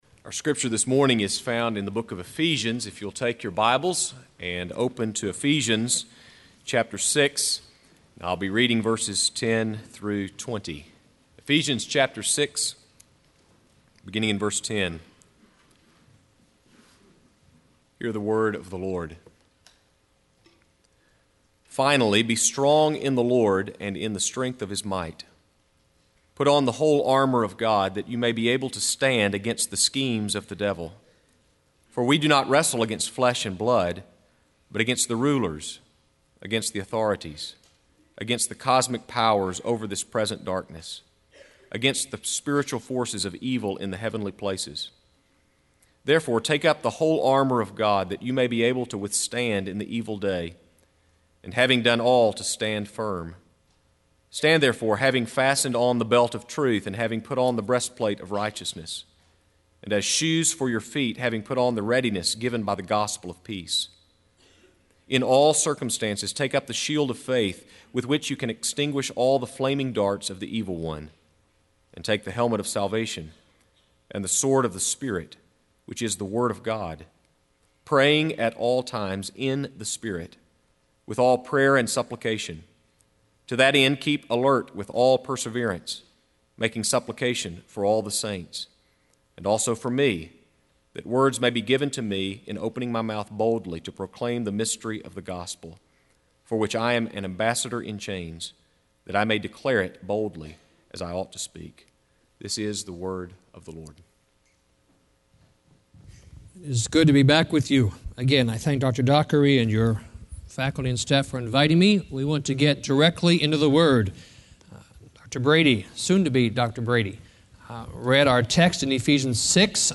Faith in Practice Conference